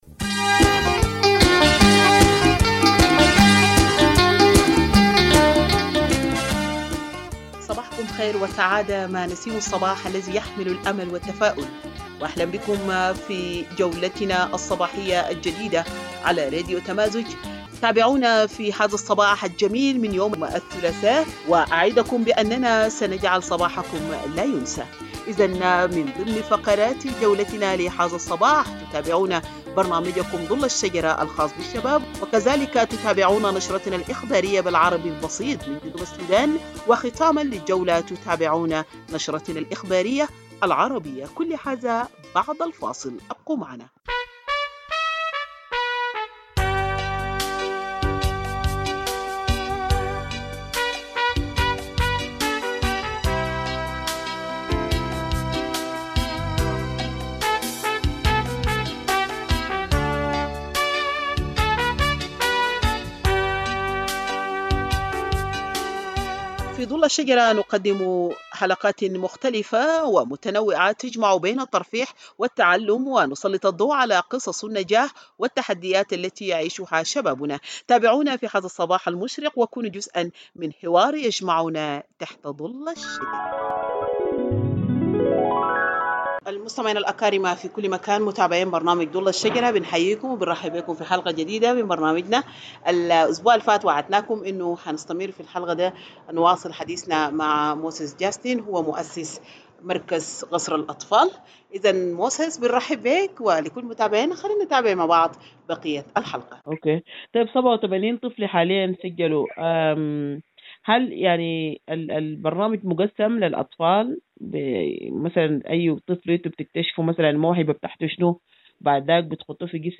Morning Broadcast 10 February - Radio Tamazuj